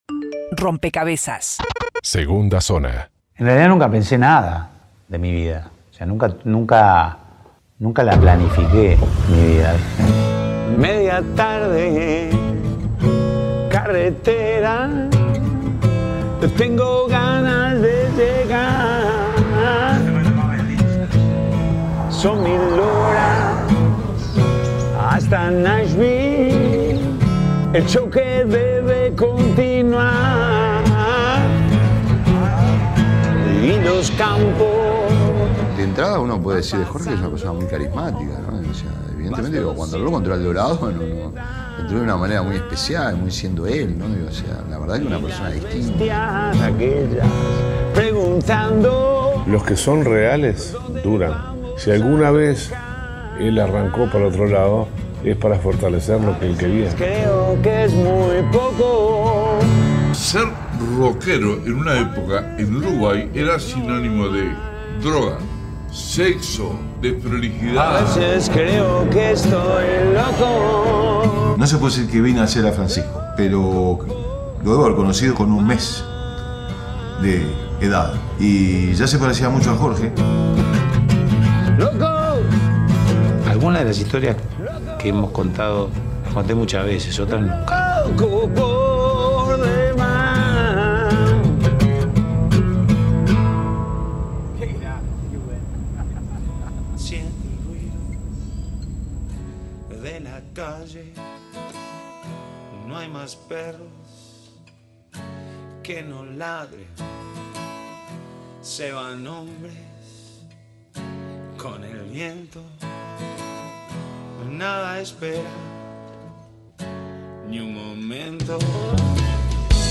Entrevista en Rompkbzas